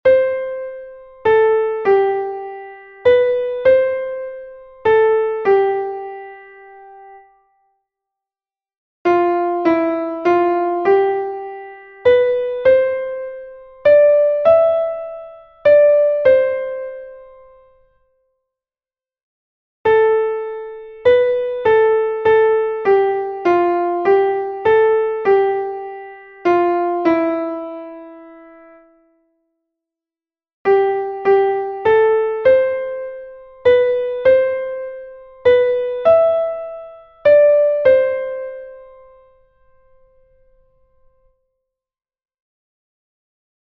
It is a compound triple meter.
leccion2_ritmo_9_8.mp3